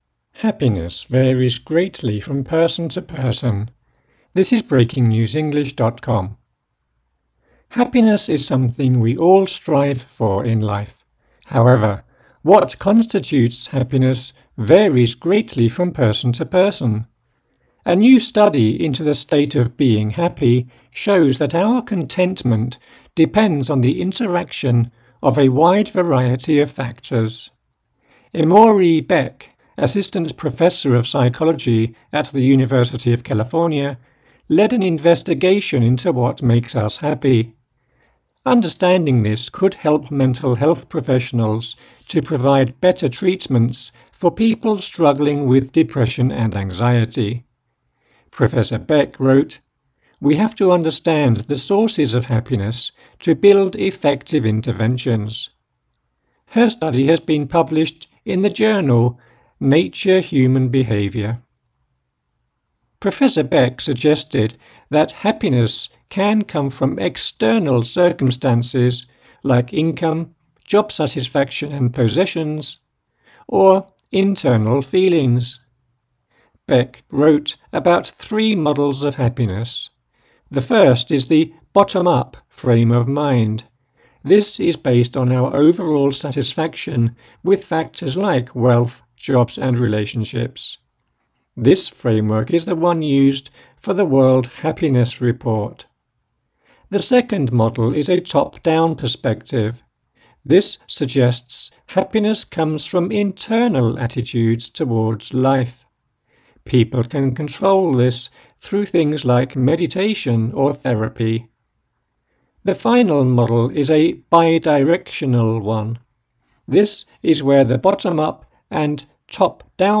AUDIO(Slow)